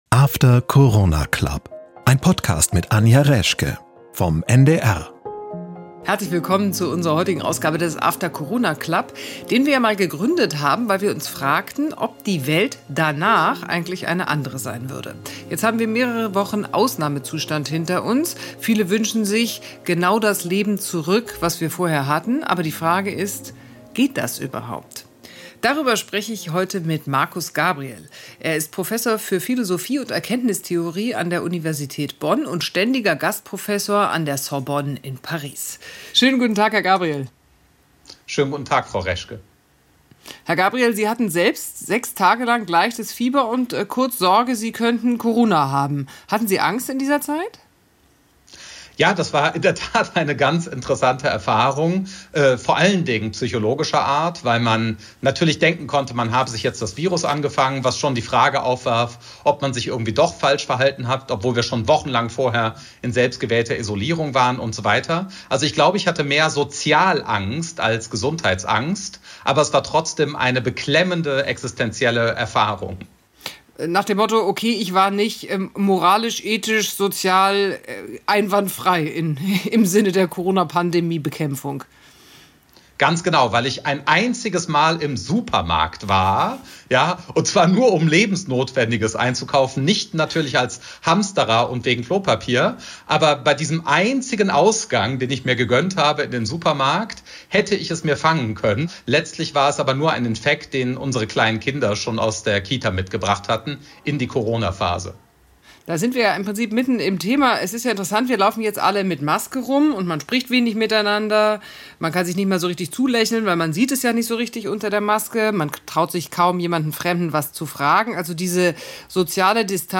Mehr noch: wir werden erkennen, dass die alte Normalität in Wahrheit selbstzerstörerisch war. Markus Gabriel ist zu Gast bei Anja Reschke im After Corona Club.